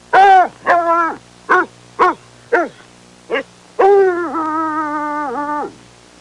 Bloodhound Sound Effect
bloodhound-2.mp3